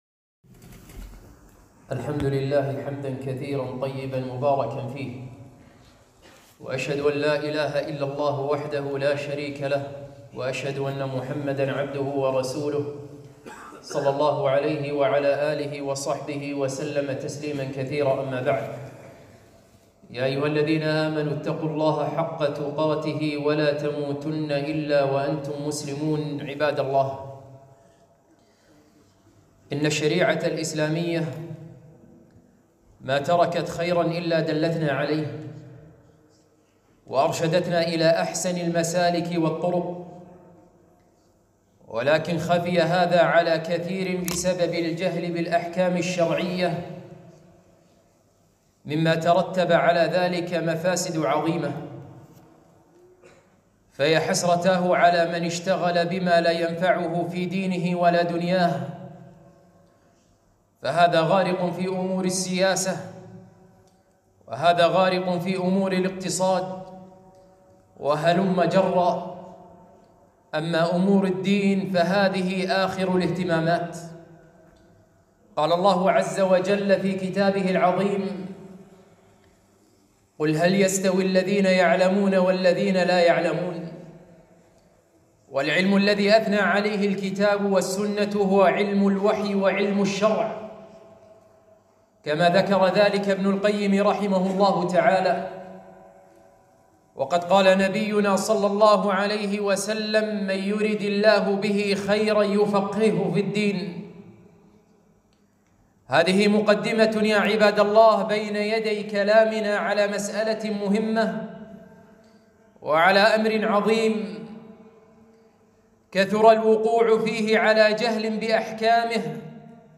خطبة - كن فقيهاً إذا قررت الطلاق